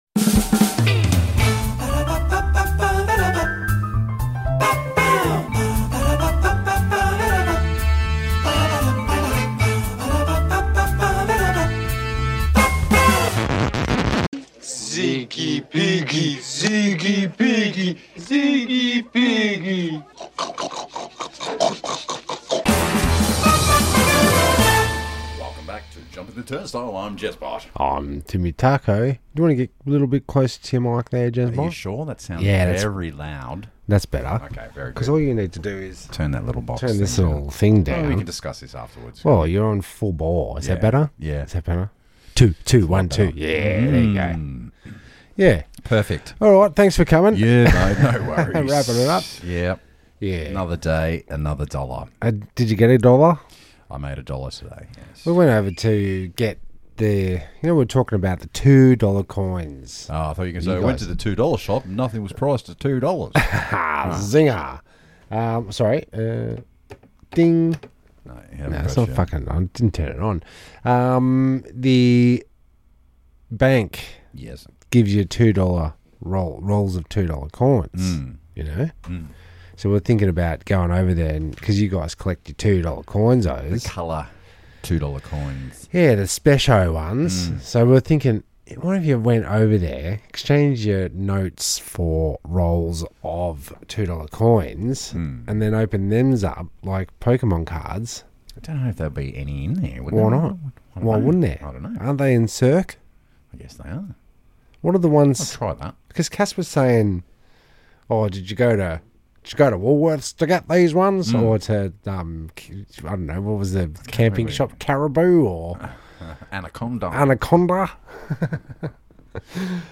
Two Brothers Sit together weekly for a catchup, sometimes with a guest host, Random topics, segments and the occasional game